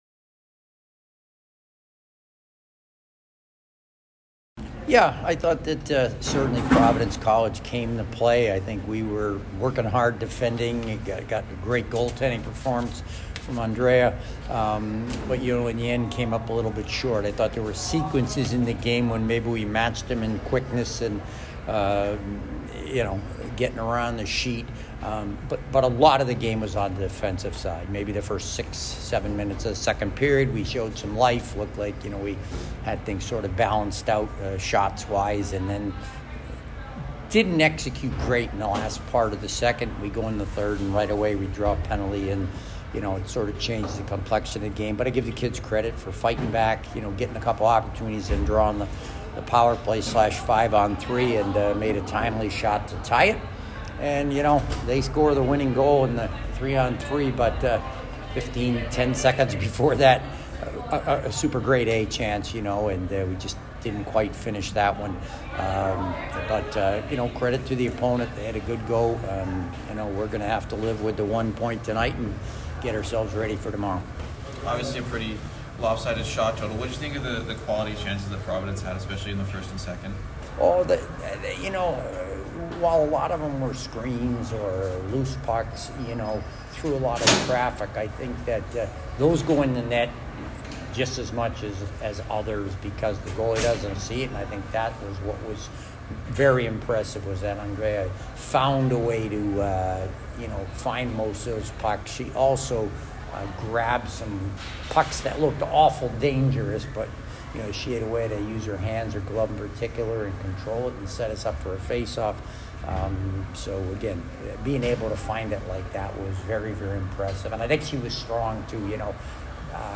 Women's Ice Hockey / Providence Postgame Interview (11-4-22)